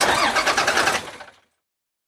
tr_sbus_startstall_02_hpx
Exterior and interior points of view of school bus starts and stalls, and air brake releases. Vehicles, School Bus Bus, School Engine, Motor